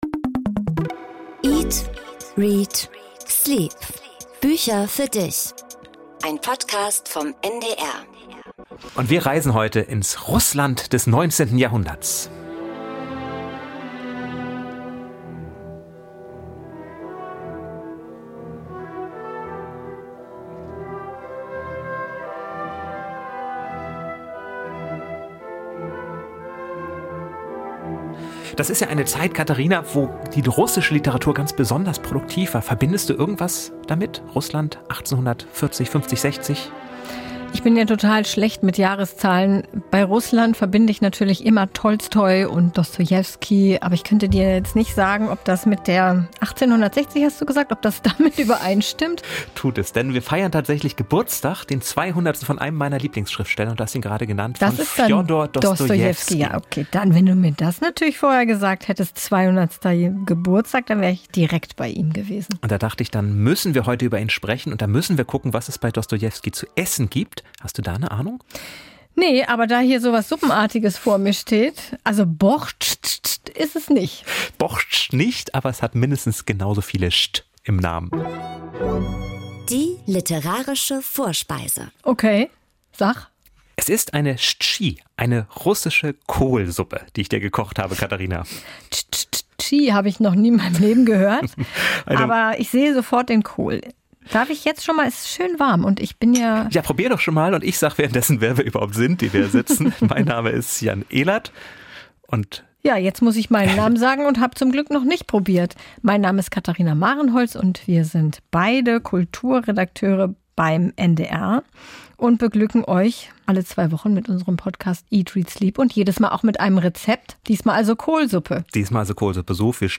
00:31:11 Interview mit Clemens Meyer